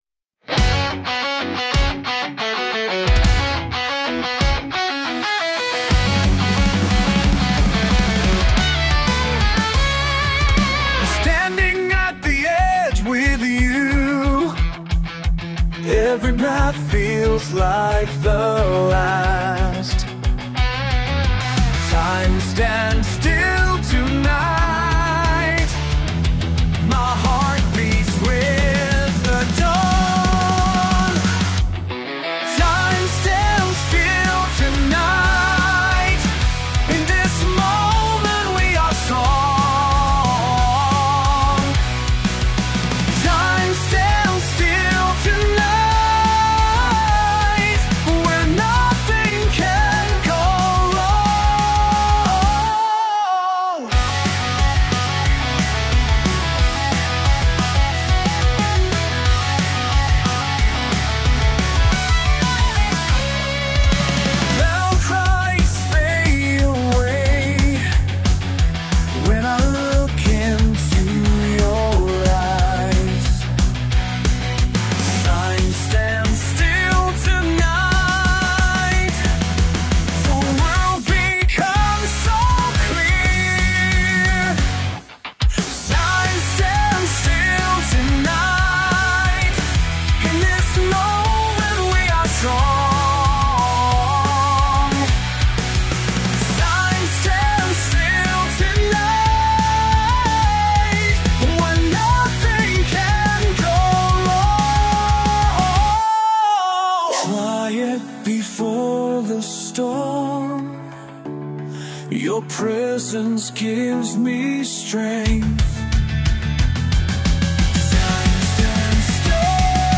プロンプト通りのロックでしたが、歌詞が英語でした。